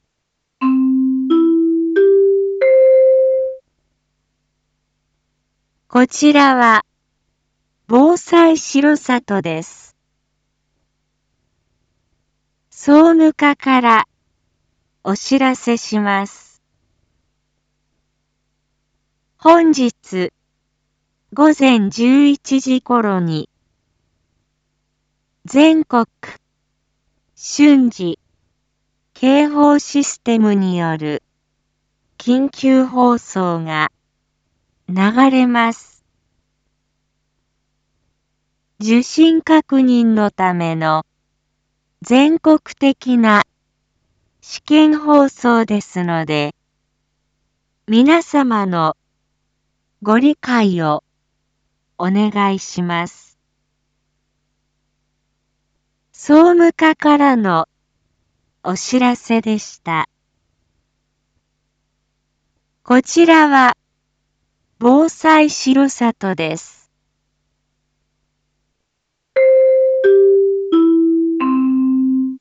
Back Home 一般放送情報 音声放送 再生 一般放送情報 登録日時：2023-08-23 07:01:14 タイトル：Jアラート試験放送について インフォメーション：こちらは、防災しろさとです。